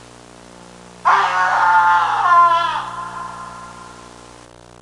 Scream Sound Effect
Download a high-quality scream sound effect.
scream-6.mp3